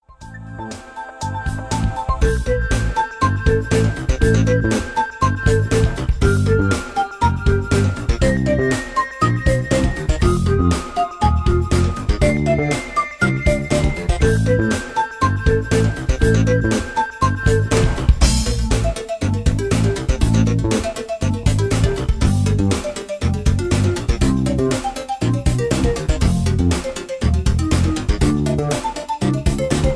Electro Ambient with Rock feel